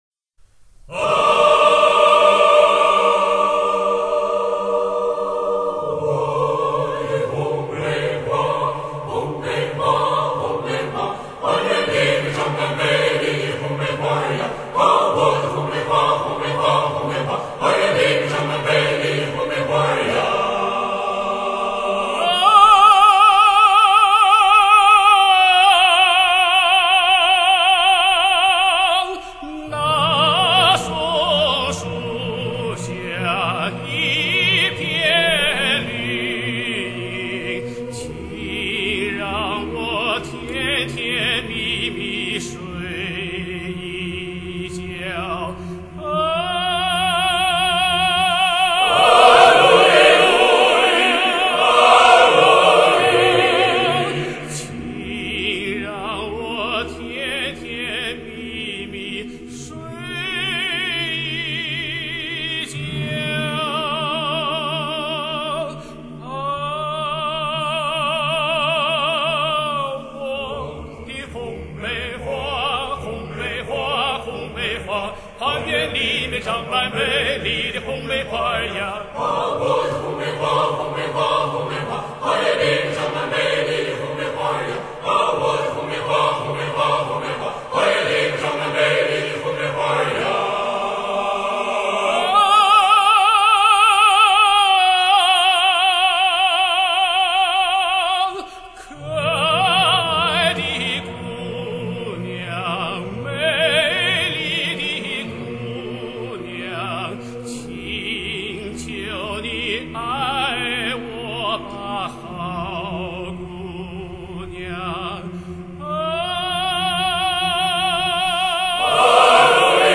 中国第一张无伴奏合唱专辑
俄罗斯民歌